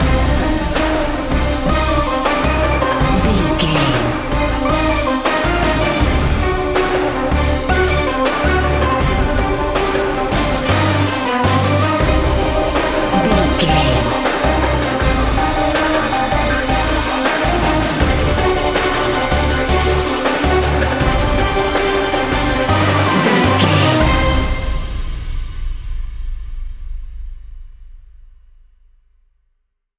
Epic / Action
In-crescendo
Aeolian/Minor
brass
drums
synthesizers